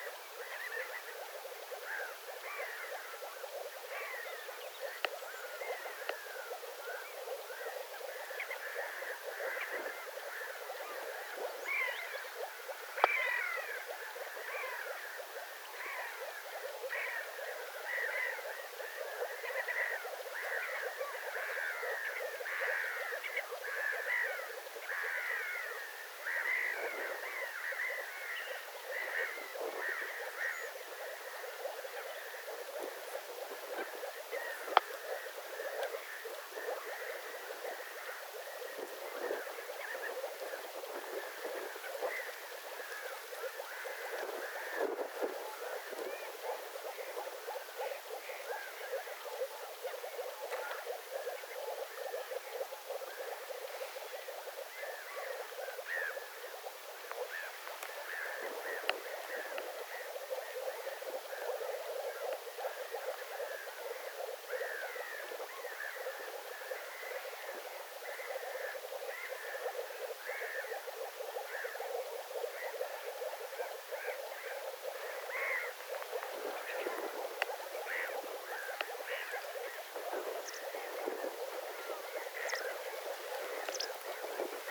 tässä kuului kevään
ensimmästä kertaa viitasammakkojen pulputusta
kevaan_ensimmaista_kertaa_kuului_viitasammakoiden_pulputusta.mp3